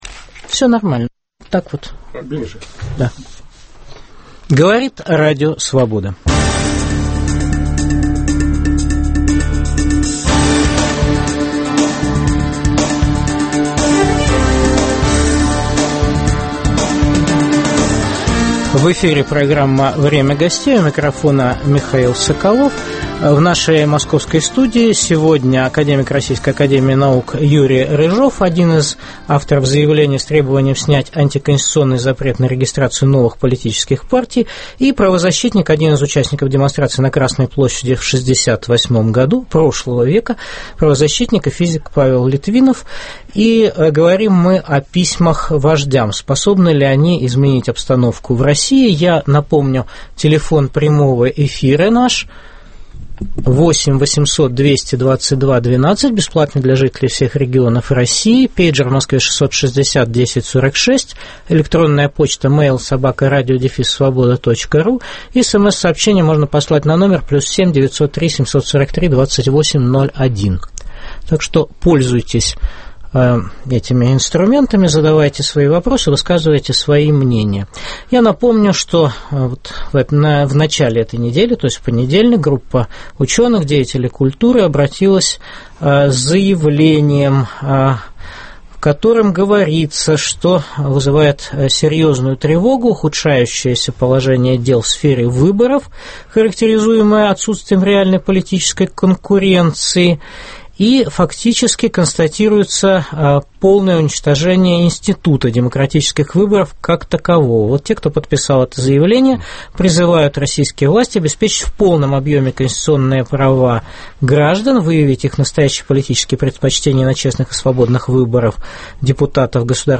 Способны ли они изменить обстановку в России. В программе участвуют один из авторов заявления с требованием снять антиконституционный запрет на регистрацию новых политических партий академик Юрий Рыжов и правозащитник, один из участников демонстрации на Красной площади в 1968 году Павел Литвинов.